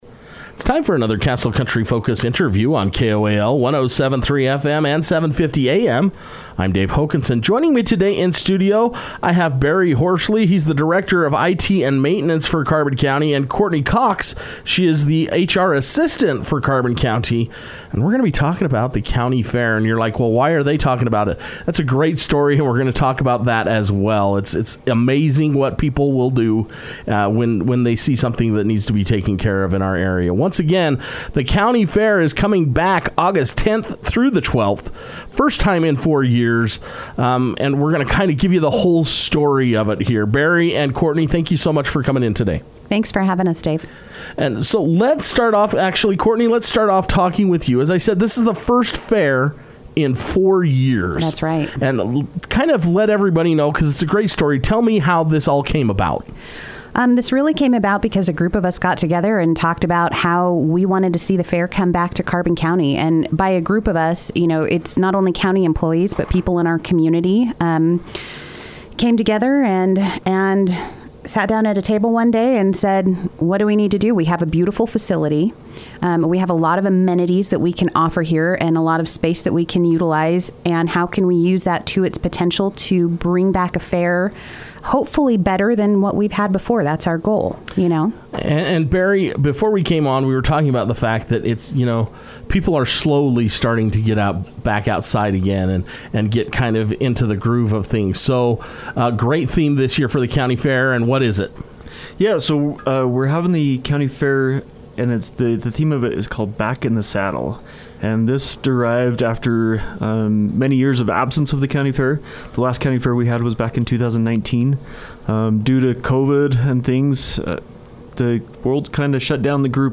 Castle Country Radio was able to sit down with county employees